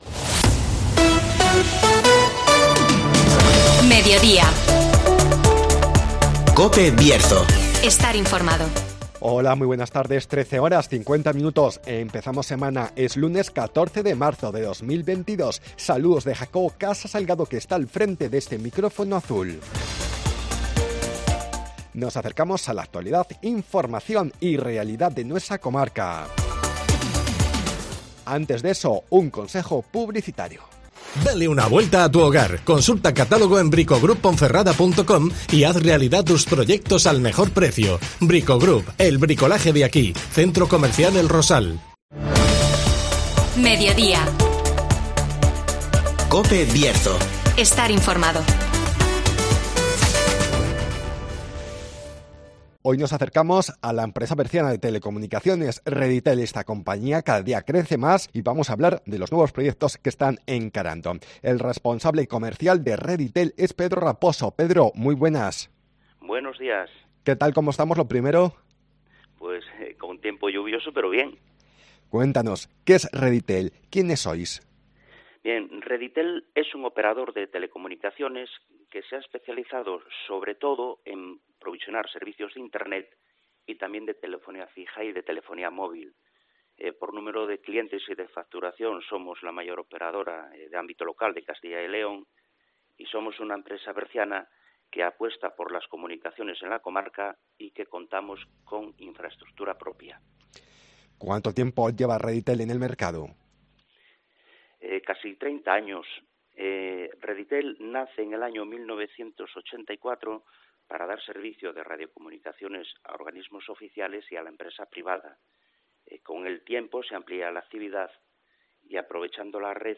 Nos acercamos a la empresa berciana de telecomunicaciones Redytel (Entrevista